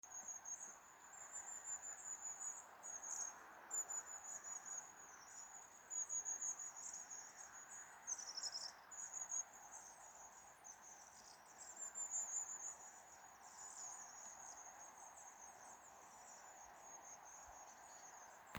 длиннахвостая синица, Aegithalos caudatus
Skaits15